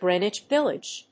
音節Gréen・wich Víllage 発音記号・読み方
/grénɪtʃ‐(米国英語)/